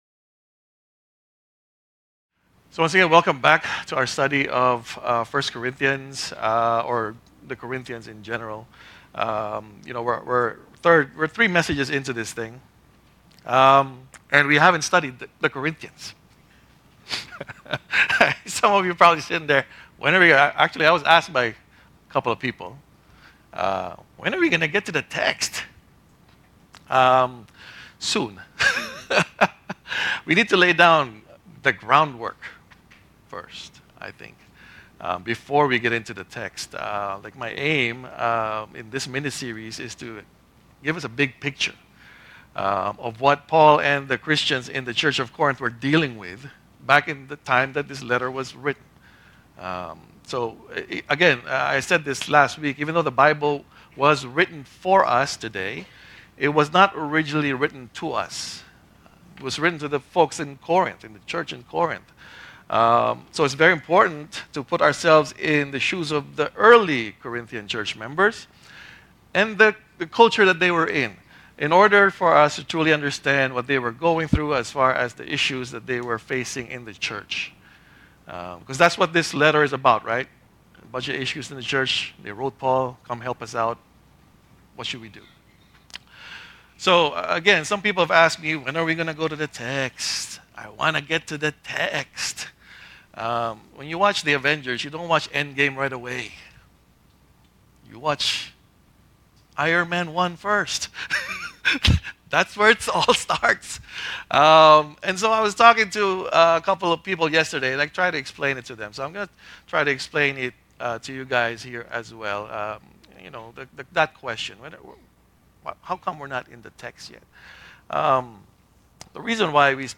In this week’s sermon, he explores the hidden worldview of individualism and how it clashes with a Christian perspective, ultimately leading to other problematic views like consumerism and moral relativism. Discover how understanding these cultural influences can help us better interpret Paul’s message for our lives today.